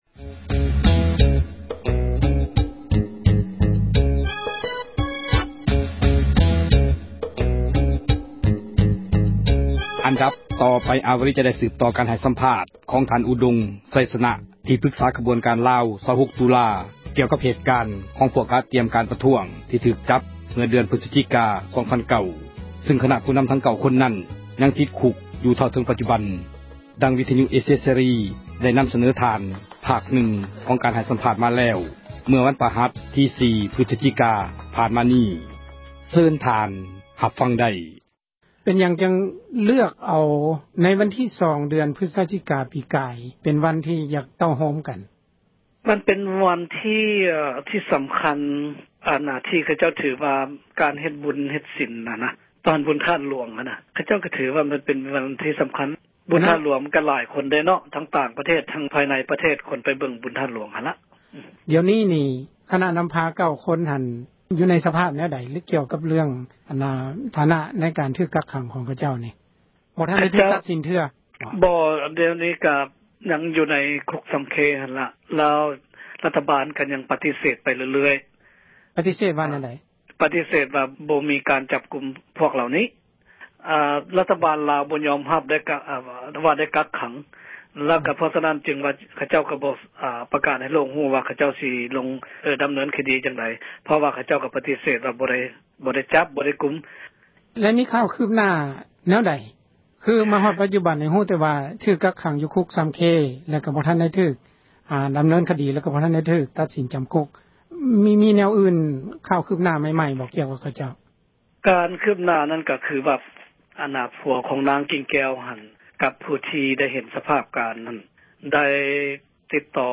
ການສໍາພາດເຖີງເຫດການ ຜູ້ນໍາ 9 ຄົນຖືກຈັບ (ຕໍ່)